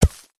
Concrete Impacts
ConcreteHit05.wav